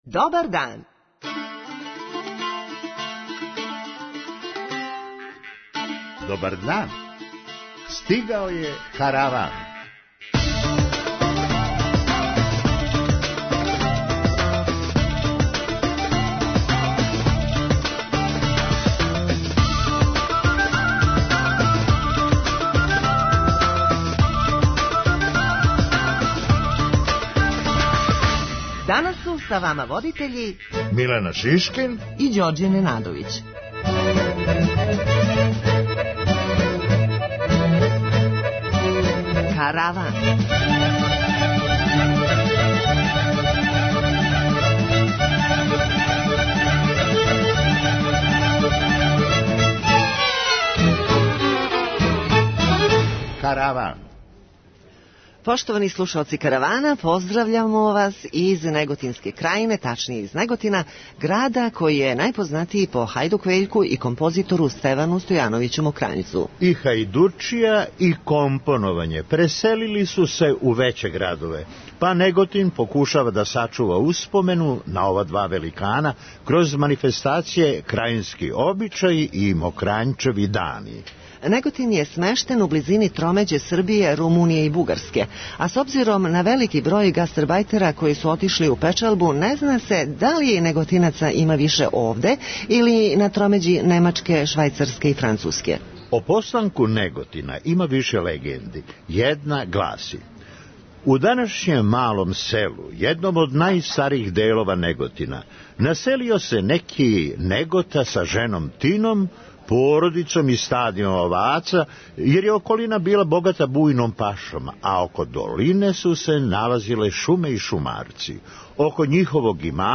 Поштовани слушаоци Каравана, данас ћемо вас поздравити из Неготинске крајине, тачније из Неготина, града који је најпознатији по Хајдук Вељку и композитору Стевану Стојановићу Мокрањцу.
преузми : 9.06 MB Караван Autor: Забавна редакција Радио Бeограда 1 Караван се креће ка својој дестинацији већ више од 50 година, увек добро натоварен актуелним хумором и изворним народним песмама.